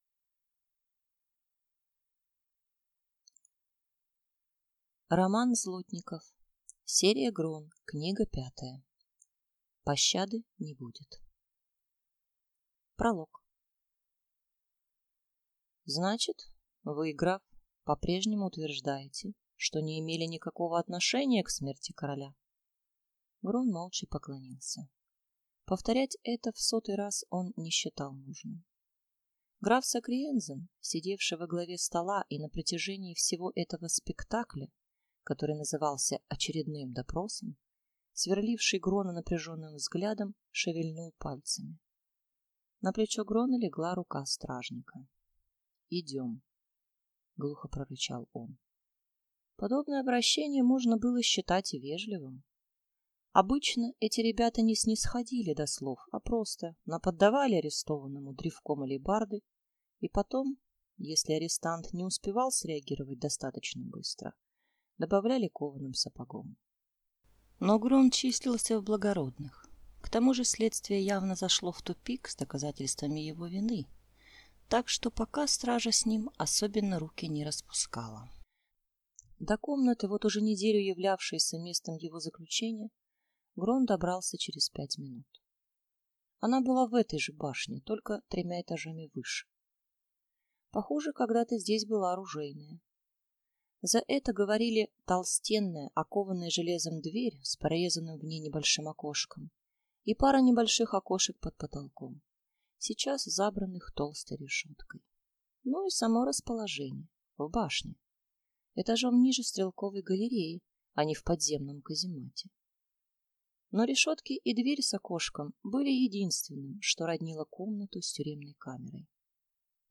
Аудиокнига Пощады не будет | Библиотека аудиокниг